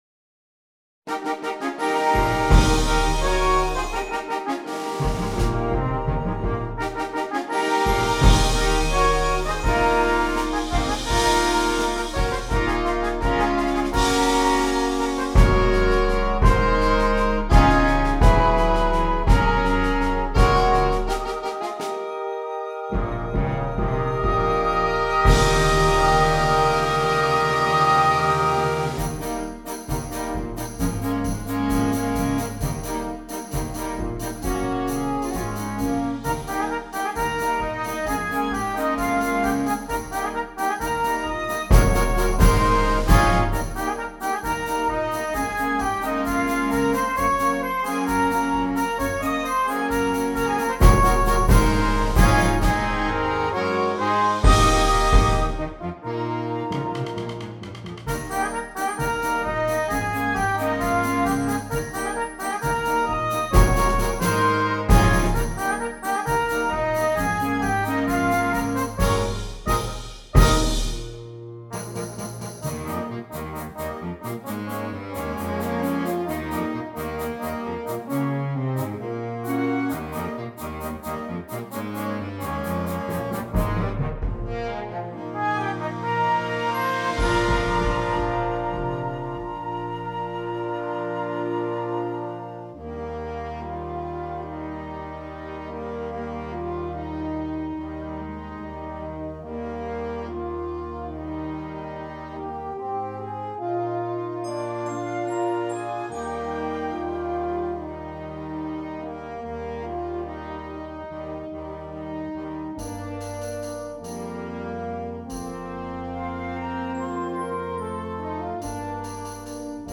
Flexible Band